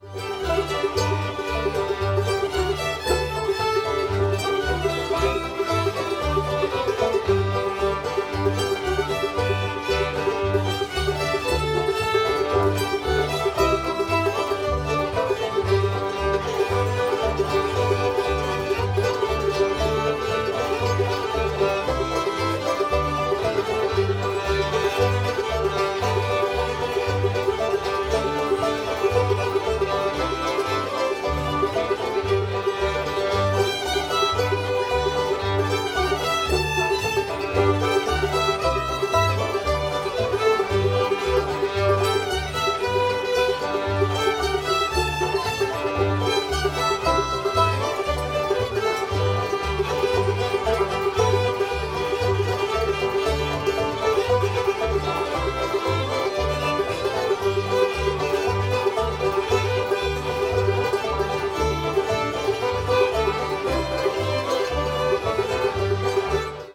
hawk's got a chicken [G]